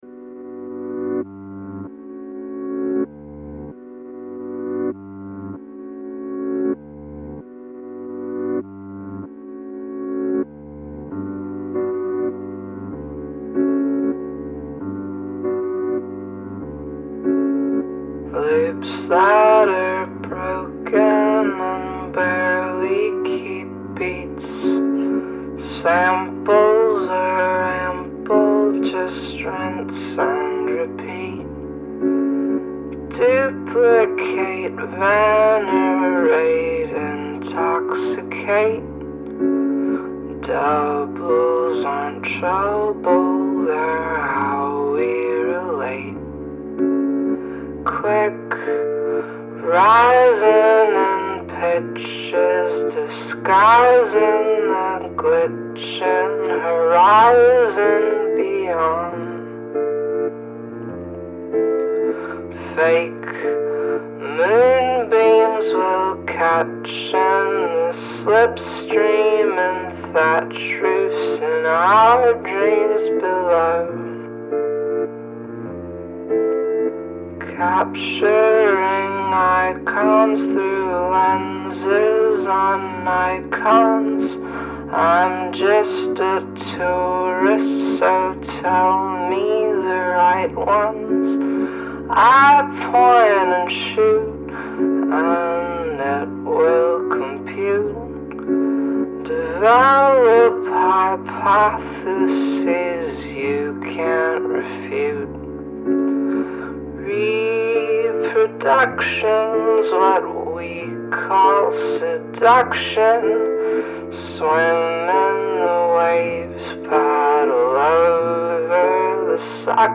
i have always recorded my music on computers.